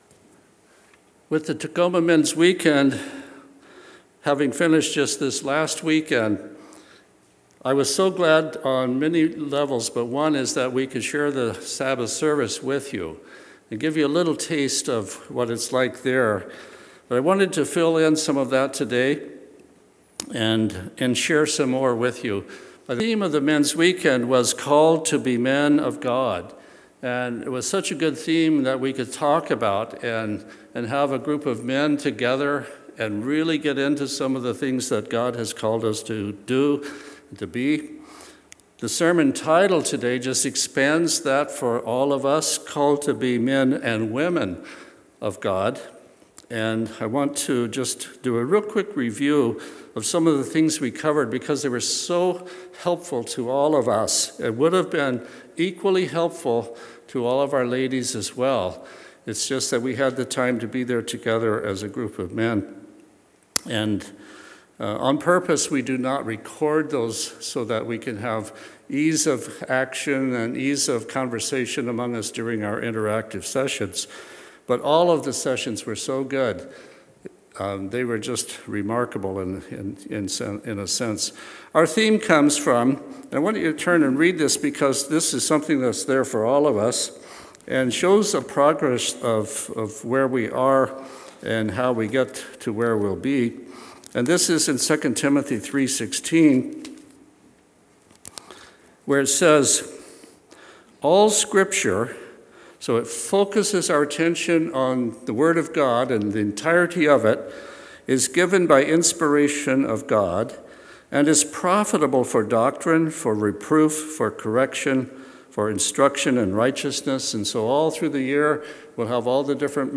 Sermons
Given in Olympia, WA Tacoma, WA